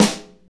Index of /90_sSampleCDs/Northstar - Drumscapes Roland/DRM_Motown/KIT_Motown Kit2x
SNR MTWN 04L.wav